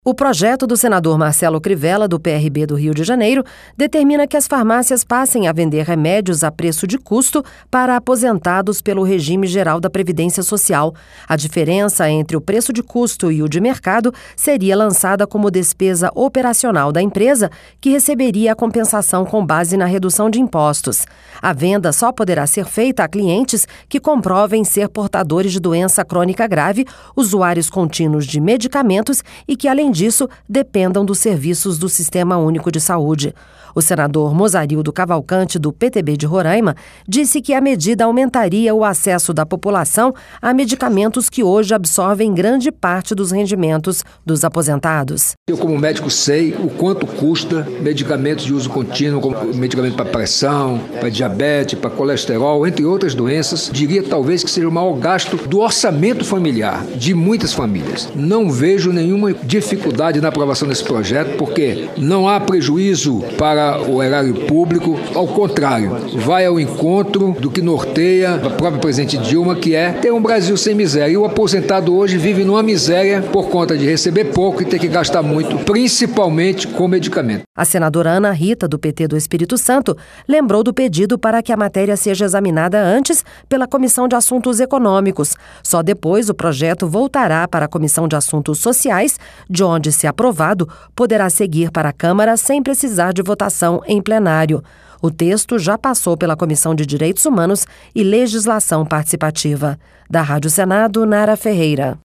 O senador Mozarildo Cavalcanti, do PTB de Roraima, disse que a medida aumentaria o acesso da população a medicamentos que hoje absorvem grande parte dos rendimentos dos aposentados.